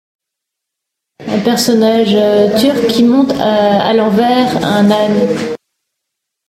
uitspraak Nasreddin